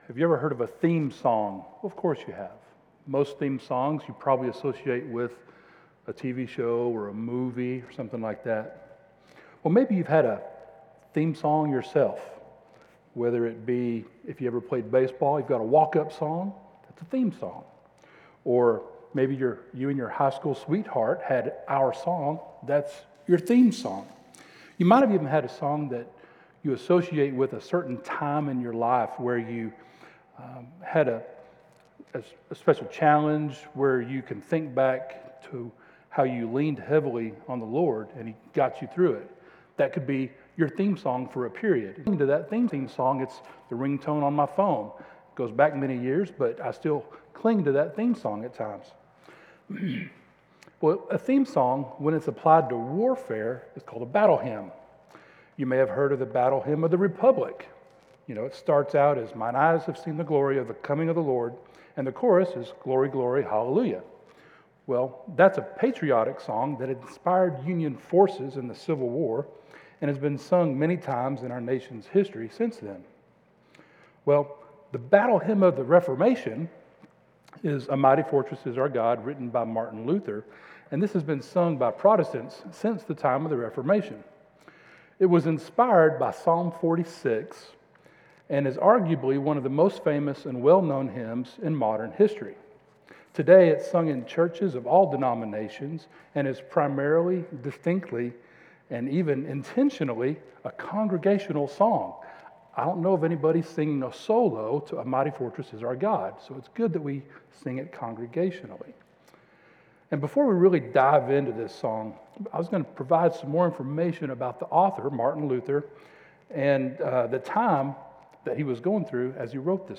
This message was delivered on Sunday evening, October 19th, 2025, at Chaffee Crossing Baptist Church in Barling, AR.